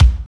26.01 KICK.wav